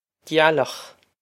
gealach gyal-okh
This is an approximate phonetic pronunciation of the phrase.